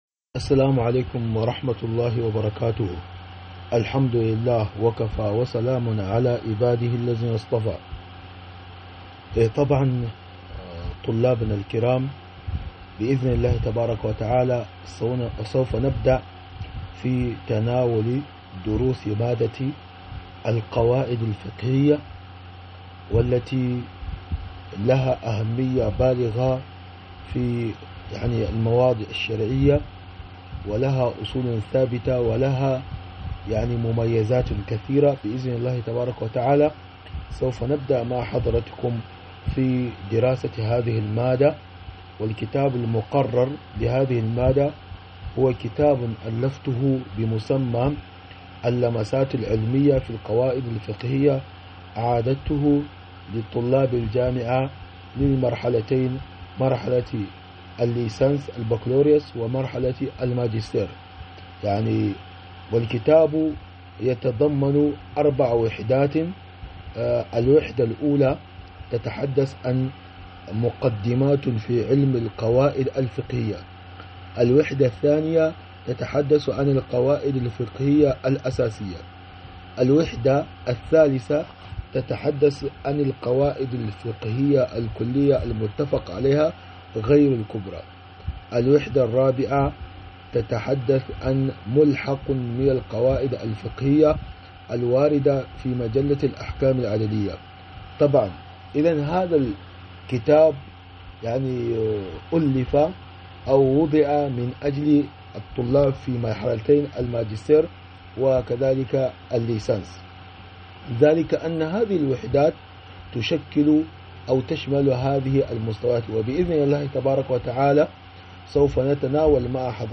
محاضرة مادة القواعد الفقهية 001